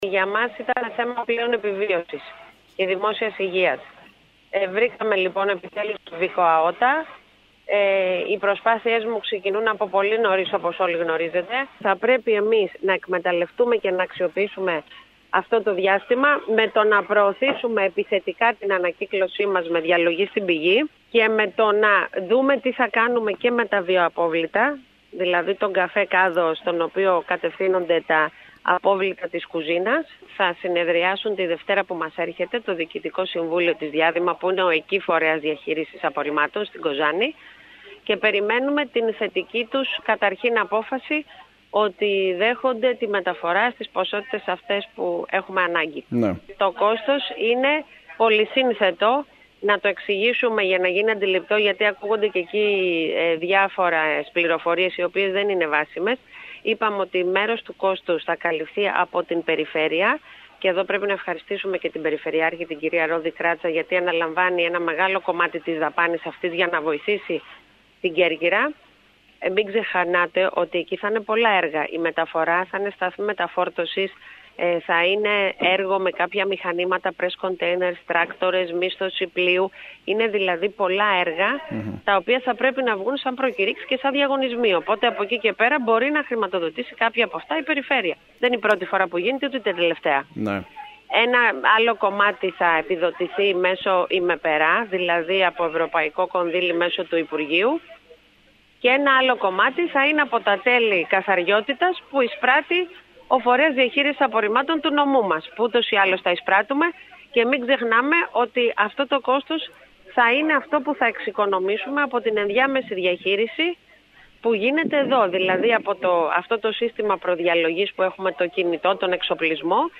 Την ικανοποίησή της για την απόφαση των συναρμόδιων υπουργείων να εγκρίνουν τη μεταφορά των απορριμμάτων της Κέρκυρας στην Κοζάνη έως ότου ολοκληρωθεί η δημιουργία του εργοστασίου ολοκληρωμένης διαχείρισης στο νησί μας, εξέφρασε η Δήμαρχος Κεντρικής Κέρκυρας Μερόπη Υδραίου μιλώντας σήμερα στο σταθμό μας.